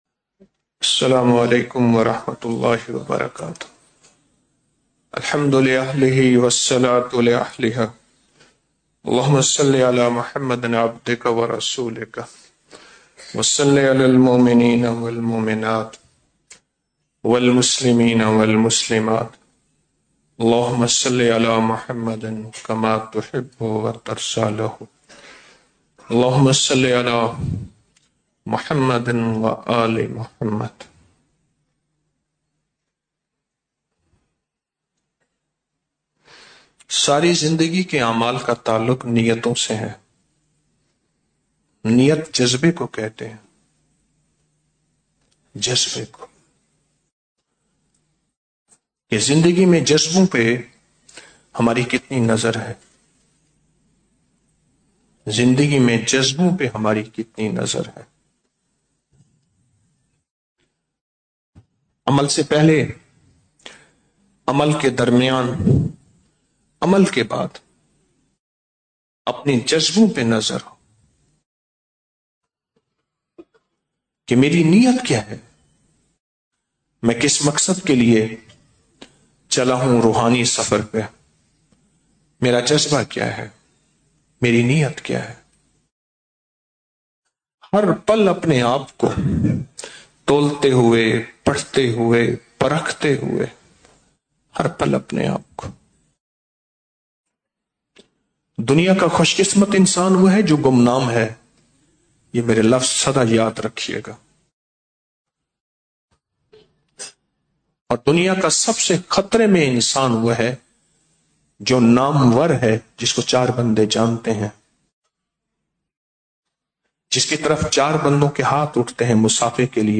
Audio Speech - 04 Ramadan After Salat Ul Taraveeh - 04 March 2025